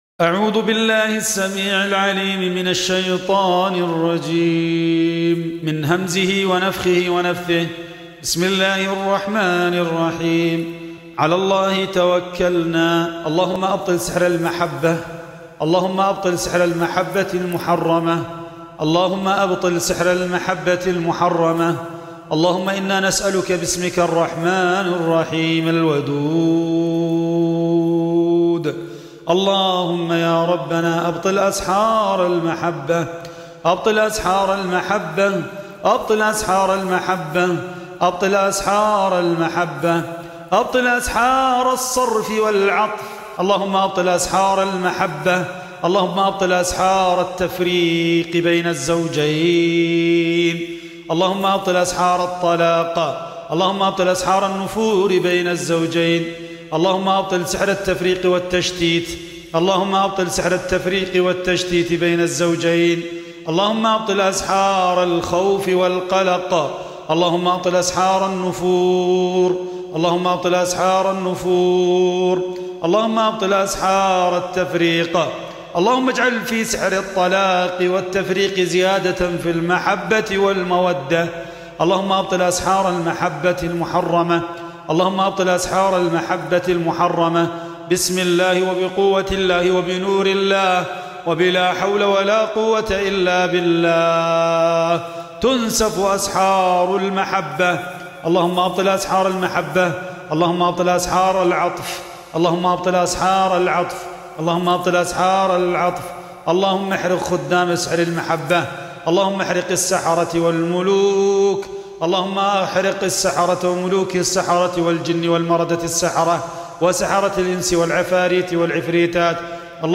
বশিকরণ যাদু নষ্টের রুকইয়াহ
বশিকরণ-যাদু-নষ্টের-রুকইয়াহ.mp3